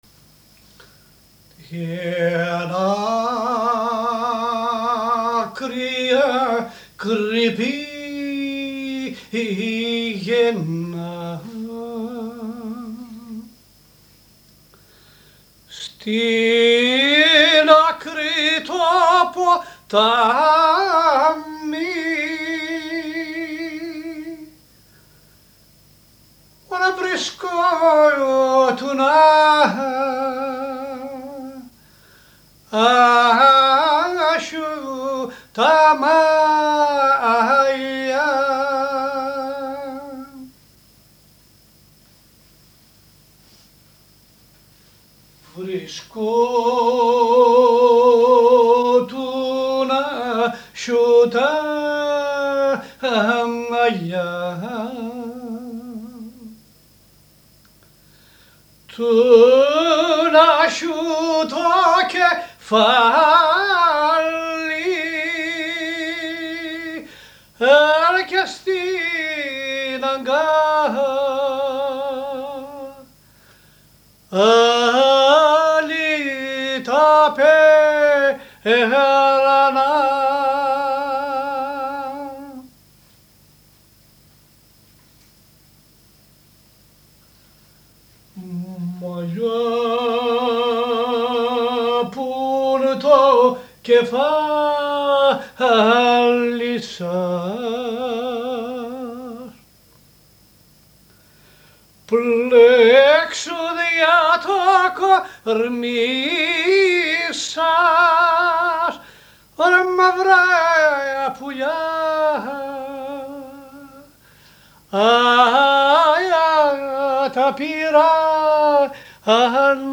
ΤΡΑΓΟΥΔΙΑ ΤΗΣ ΣΑΜΑΡΙΝΑΣ